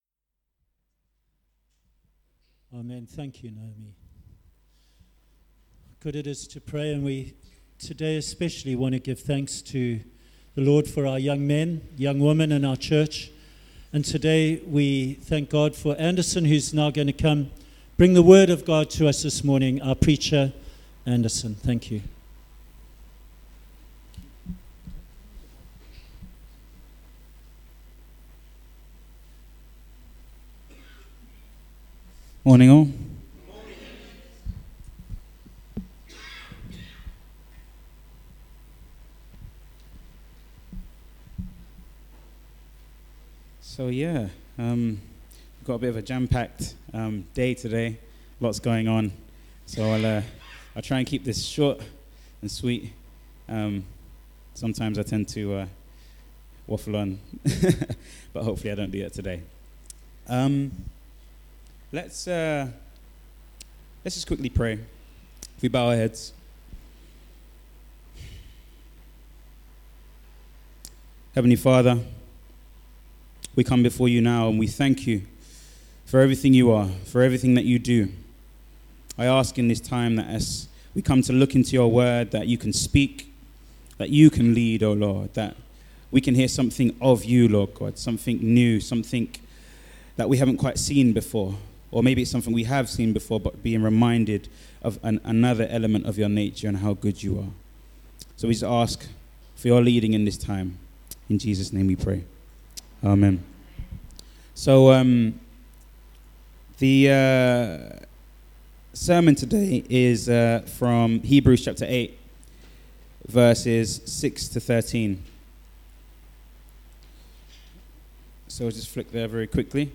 A Sunday sermon